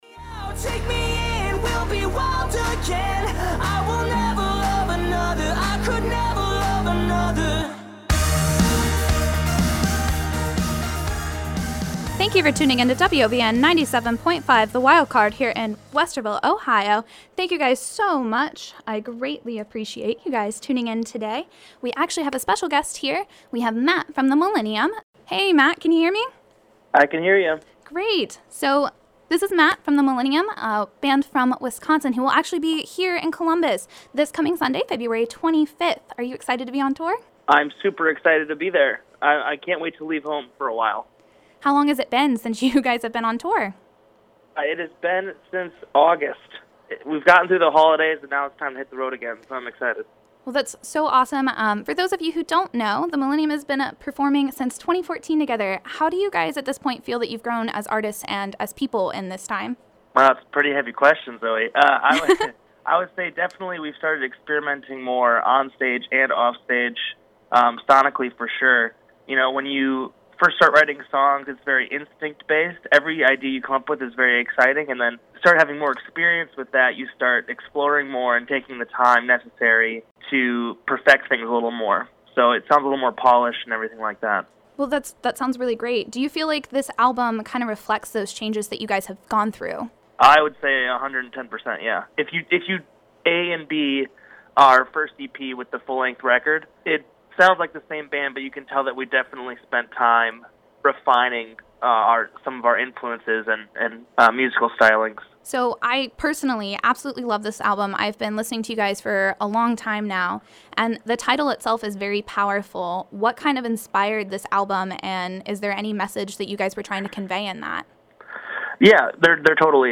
Hear the full interview before the band makes its way to Columbus, February 25 as they headline the Donato’s Basement on their “But Do We Ask Ourselves” tour.